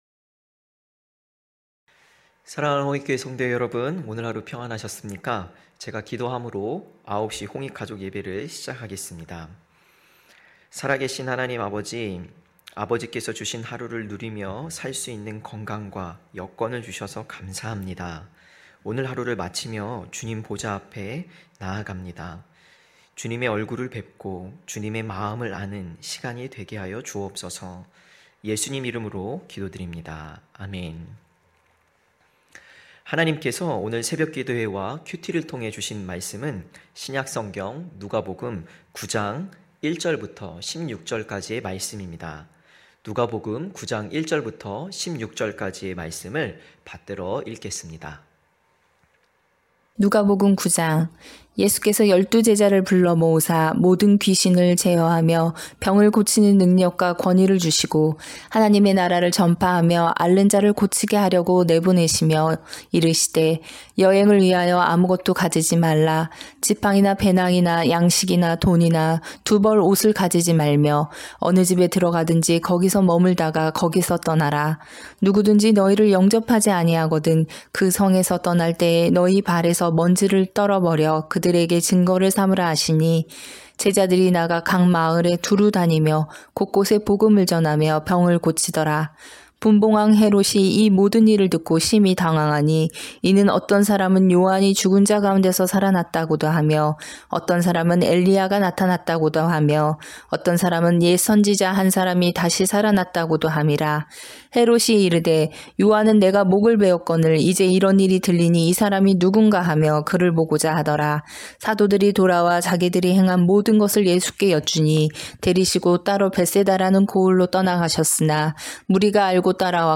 9시홍익가족예배(1월26일).mp3